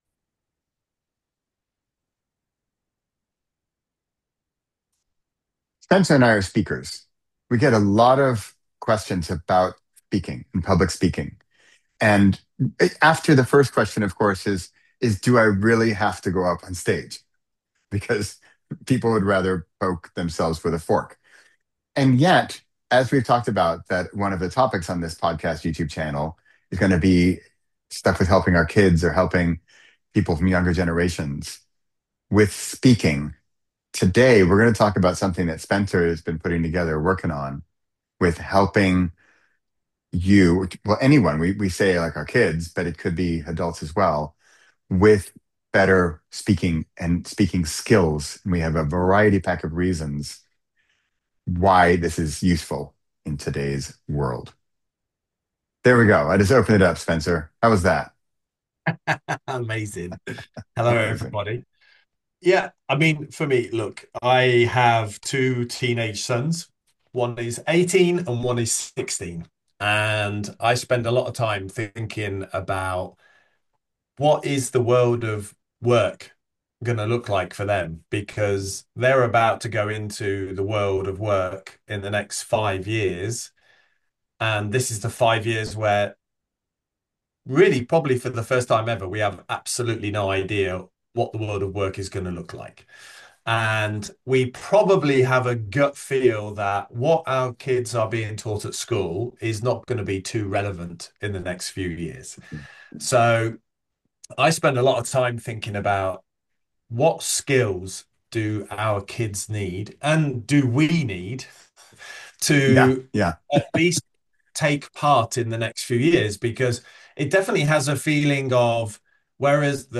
Conversations about work, AI, communication, and being human—without hype, fear, or fluff.